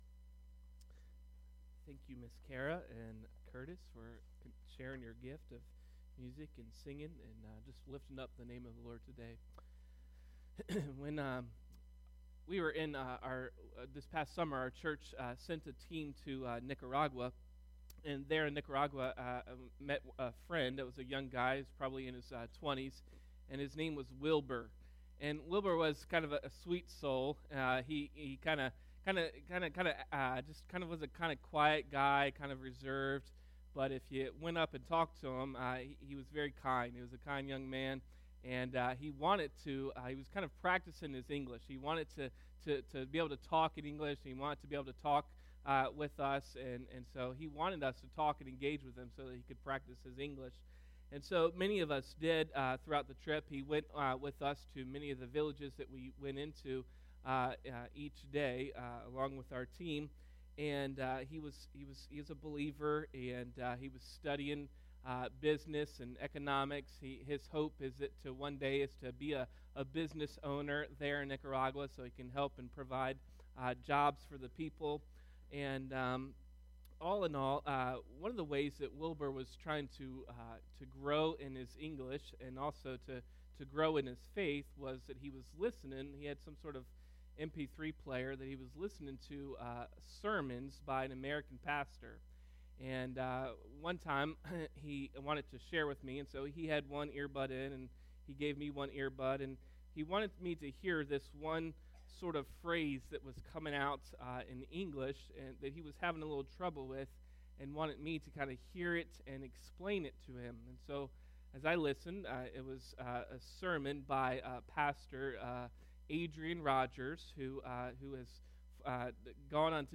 Today’s message is about pĕlōnı̂ almōnı̂.